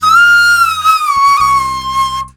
FLUT 03.AI.wav